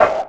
PERC48  03-R.wav